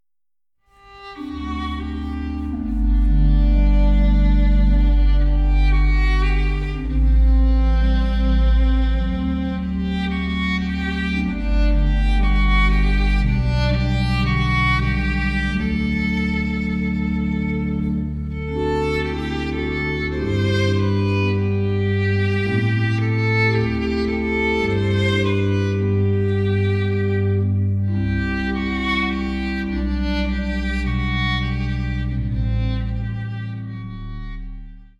Streichensemble, Orgel